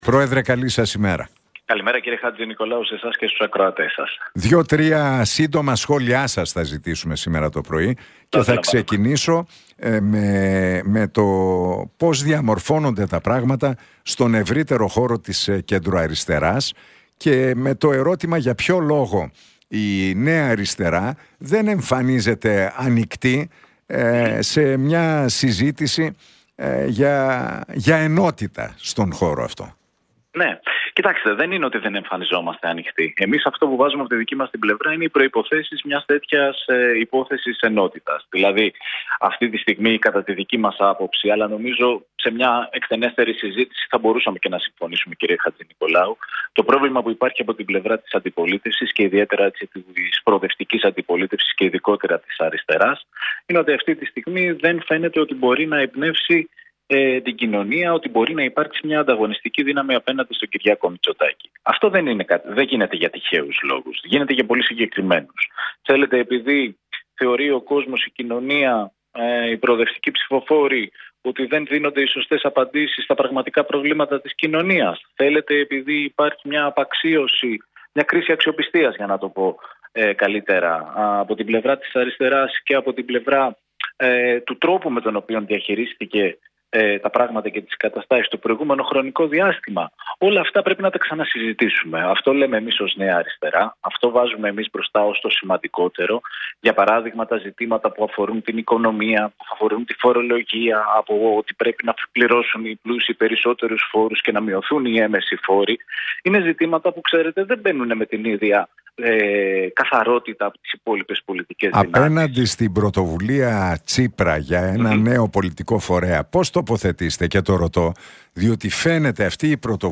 Σακελλαρίδης στον Realfm 97,8 για Τσίπρα: Προσπαθεί να είναι θελκτικός σε πολλά ακροατήρια, με αποτέλεσμα να υπάρχει ένα θολό πολιτικό στίγμα